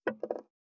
568肉切りナイフ,まな板の上,包丁,ナイフ,
効果音